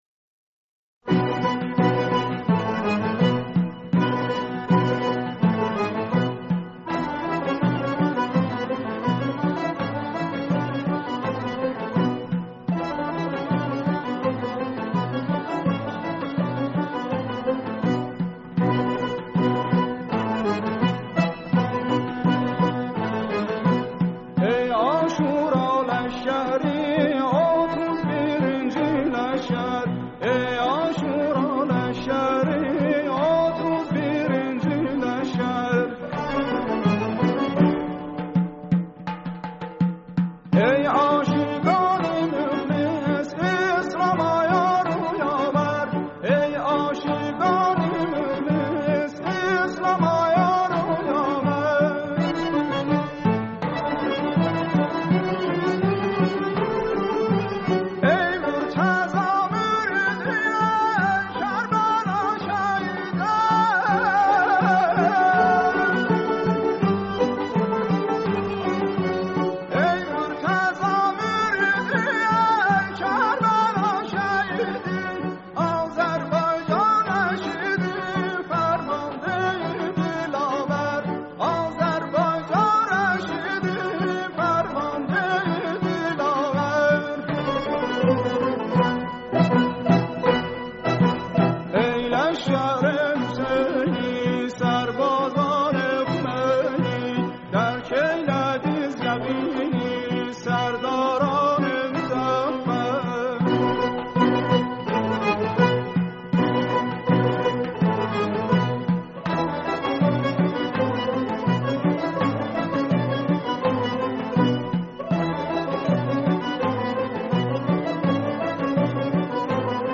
ترانه ی آذری با مضمون دفاع مقدس/شاعر
شیوه اجرا: اركستر